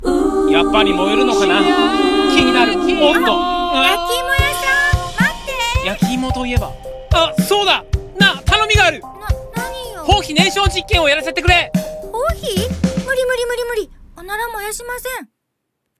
ゼペット動画声劇「放屁燃焼実験」